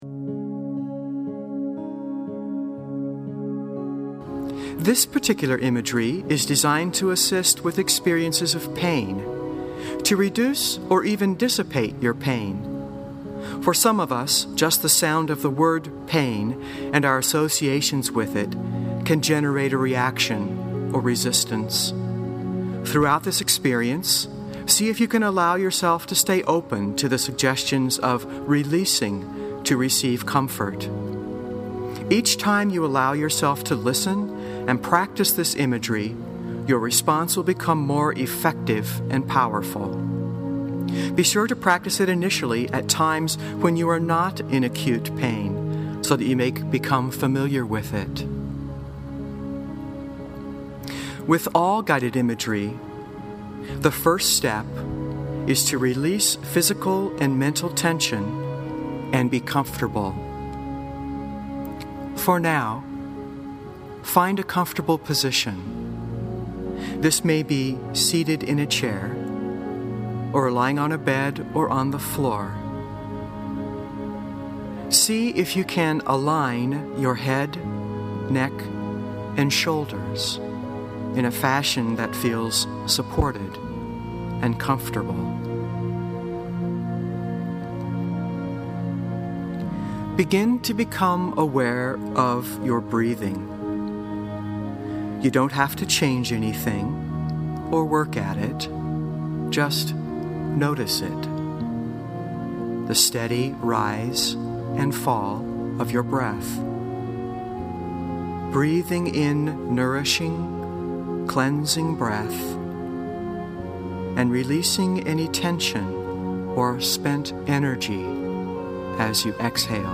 Guided Imagery Podcasts